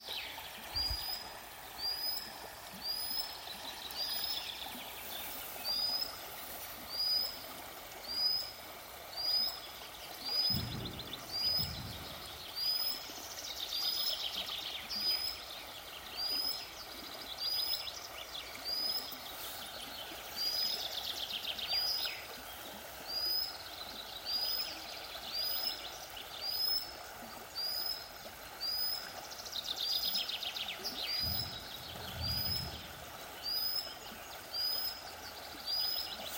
Putni -> Bridējputni ->
Upes tilbīte, Actitis hypoleucos
StatussUztraukuma uzvedība vai saucieni (U)